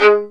Index of /90_sSampleCDs/Roland L-CD702/VOL-1/STR_Violin 2&3vb/STR_Vln3 _ marc
STR VLN JE0K.wav